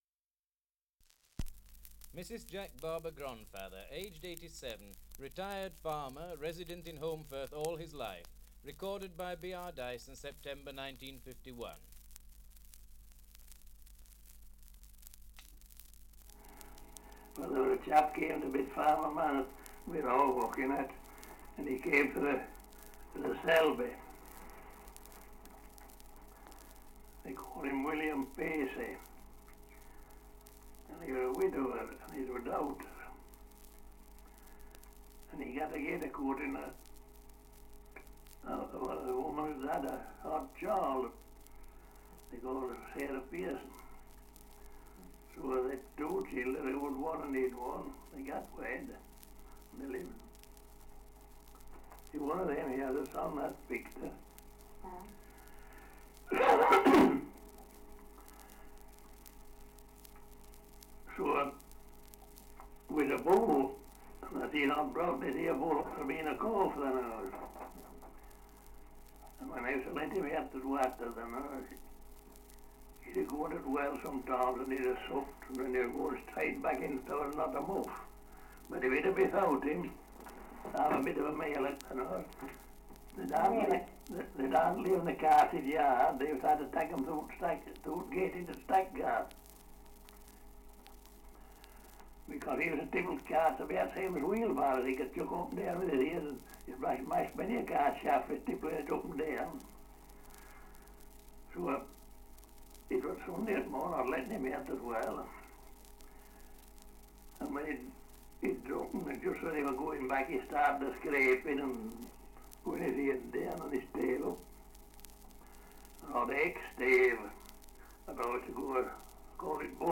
Dialect recording in Holmbridge, Yorkshire
78 r.p.m., cellulose nitrate on aluminium